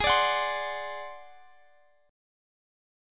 Ab7b5 Chord
Listen to Ab7b5 strummed